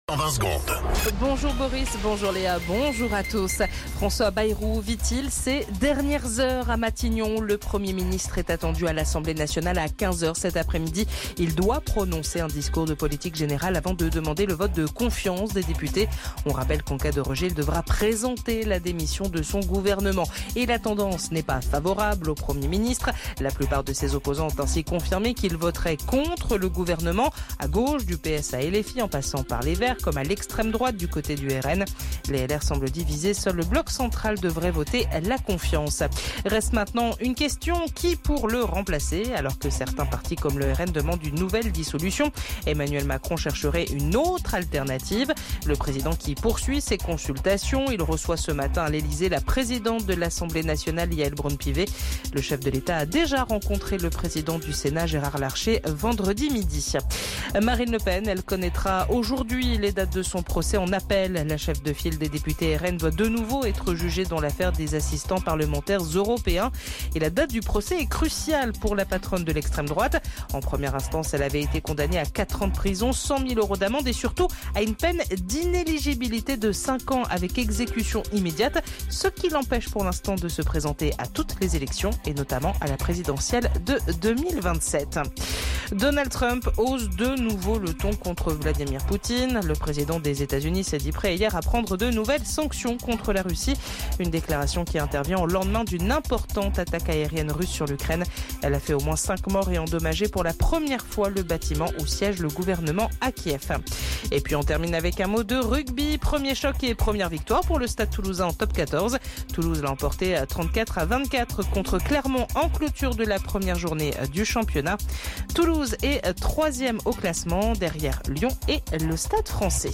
Flash Info National 08 Septembre 2025 Du 08/09/2025 à 07h10 .